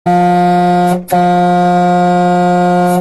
Klingelton Truck
Kategorien Soundeffekte